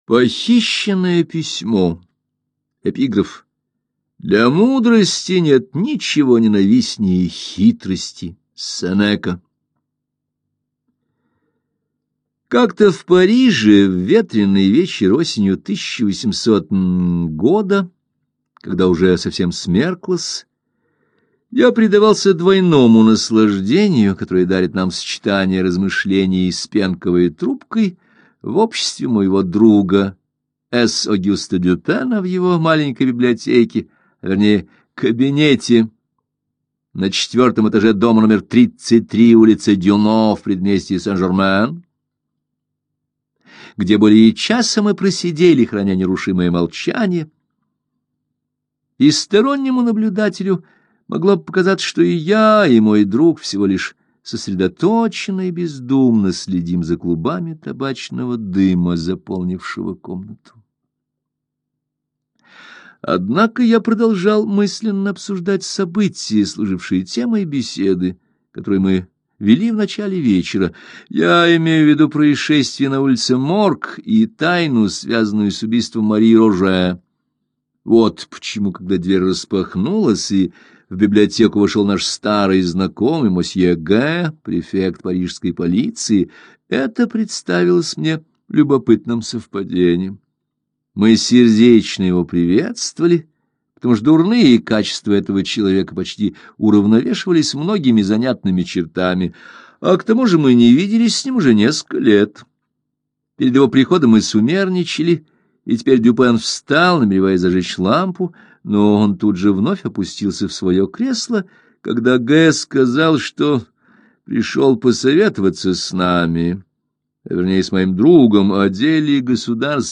Похищенное письмо - аудио рассказ Эдгара По - слушать онлайн